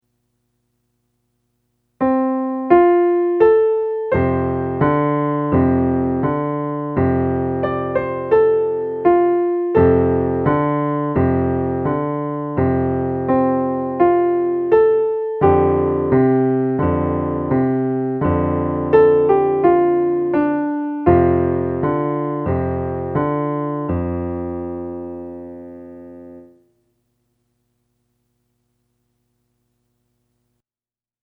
for easy playing by little hands
plus 18 more classic cowboy tunes.